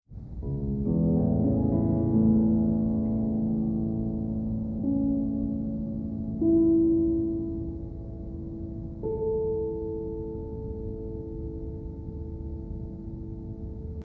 The first movement begins mysteriously.
The Tempest sonata starts with something that would be more like a question.